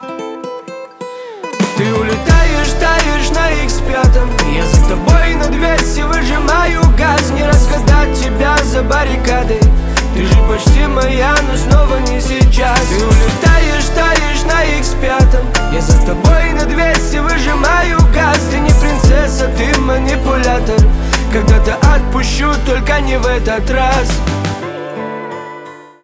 грустные
печальные , гитара
басы